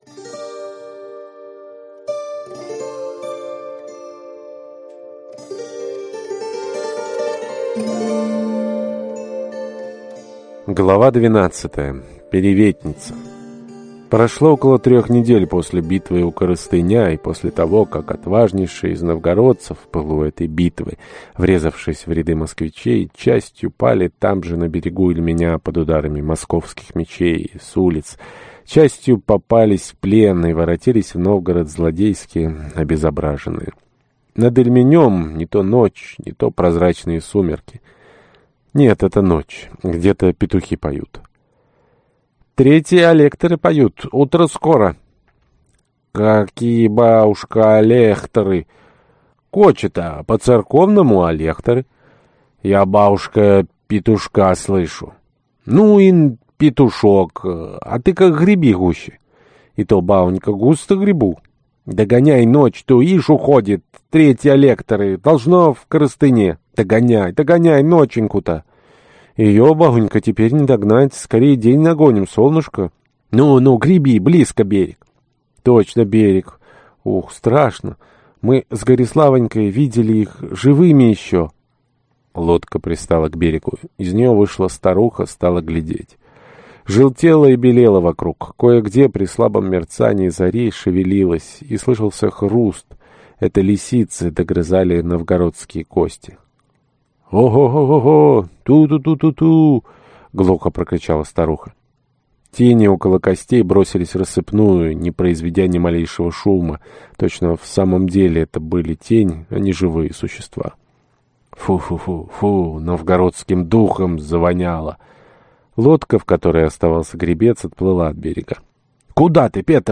Аудиокнига Господин Великий Новгород. Наносная беда | Библиотека аудиокниг